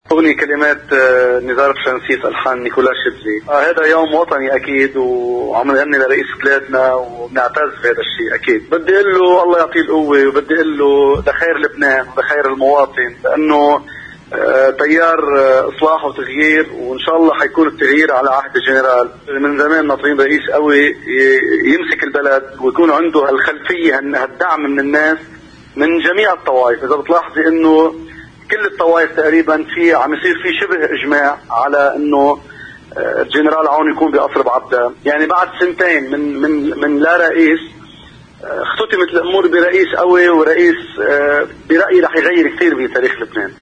في اتصال مع الـ”OTV” كشف الفنان ملحم زين عن تحضيره لعمل فني بمناسبة وصول العماد ميشال عون رئيساً للجمهورية، قال: